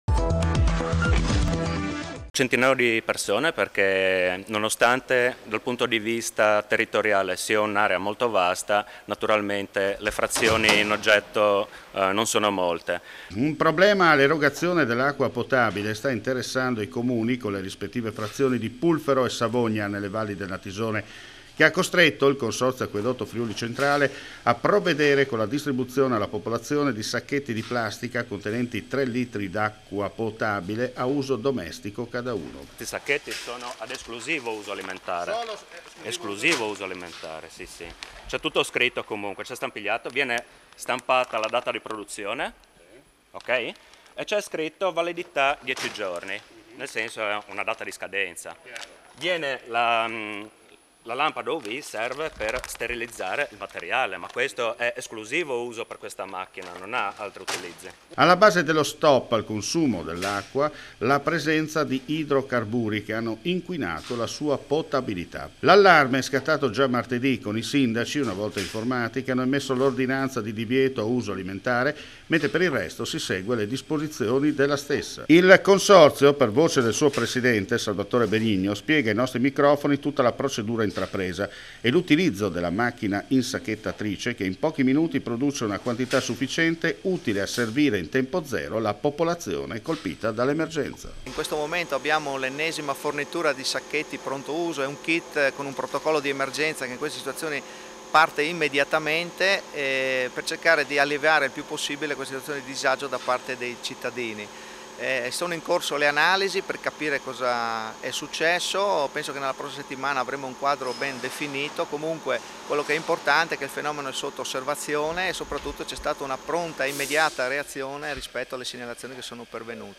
FRIULITV GIORNALE RADIO: LE ULTIME AUDIONOTIZIE DAL FVG